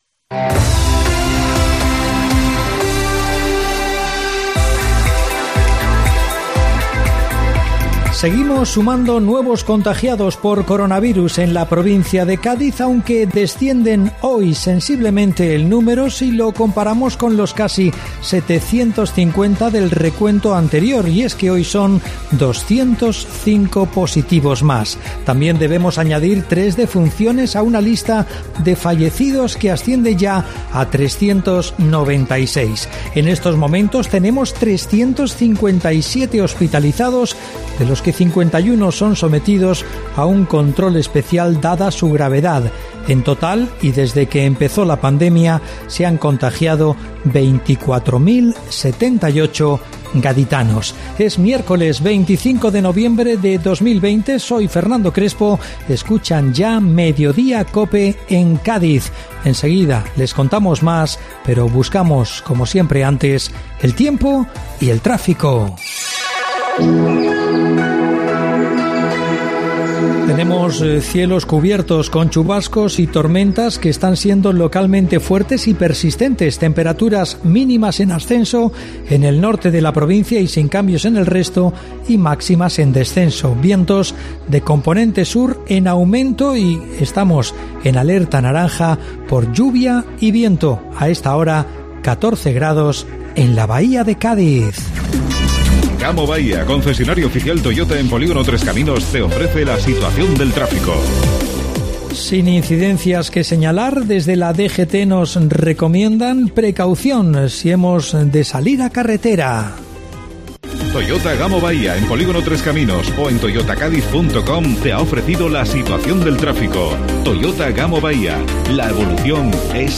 Informativo Mediodía COPE Cádiz (25-11-20)